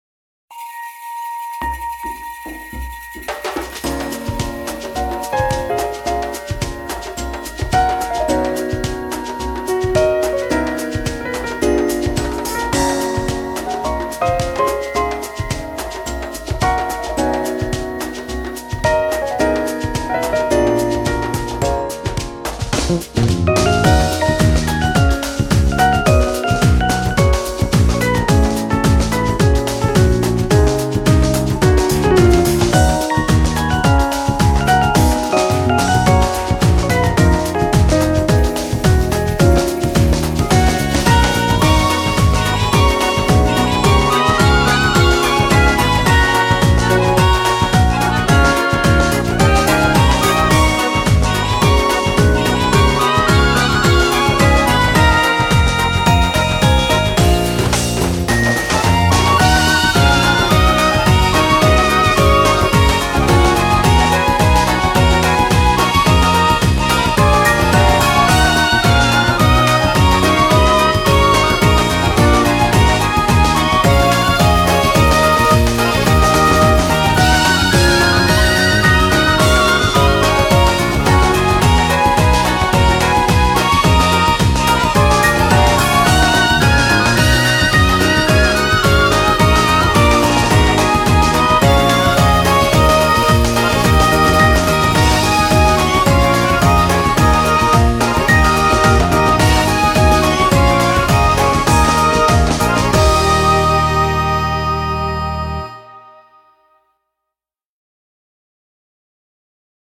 BPM87-108
Audio QualityPerfect (High Quality)
Comments[WORLD/ELECTRONICA]